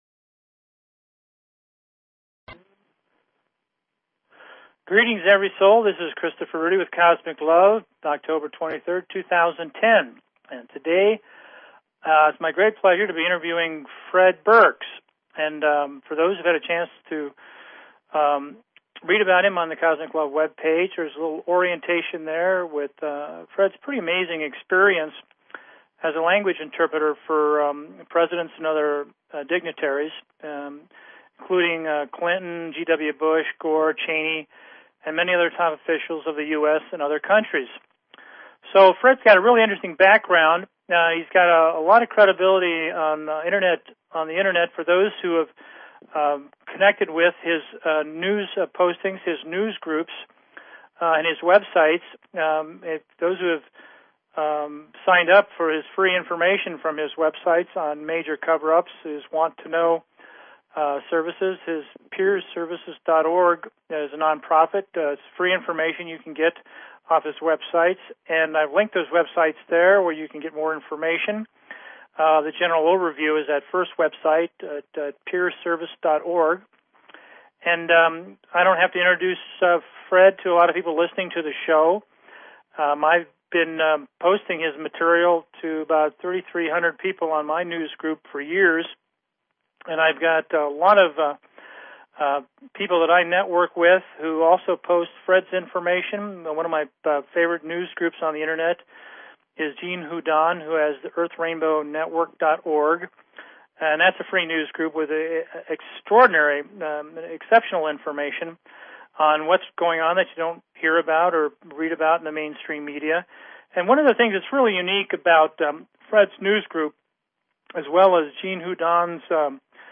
Talk Show Episode, Audio Podcast, Cosmic_LOVE and Courtesy of BBS Radio on , show guests , about , categorized as